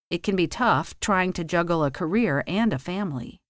Simplesmente é o “t” no início, com o som do “f” no final: /tʌf/.